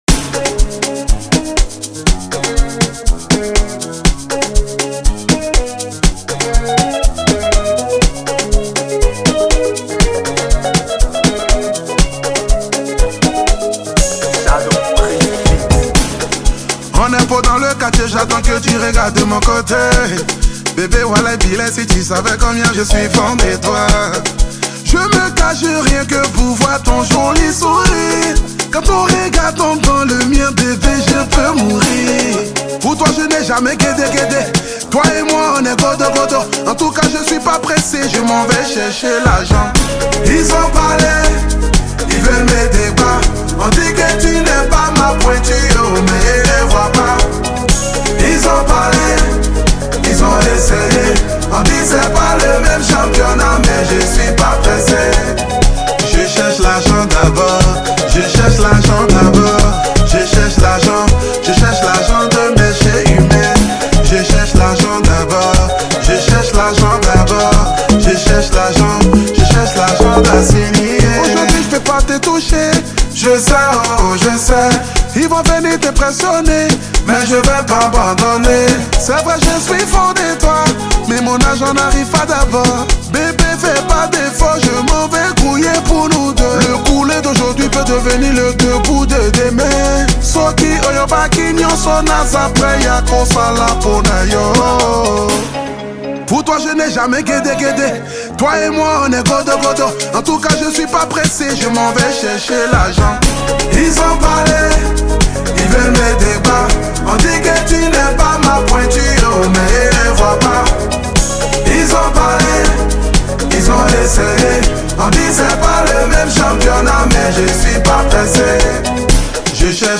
| Afro décalé